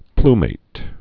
(plmāt)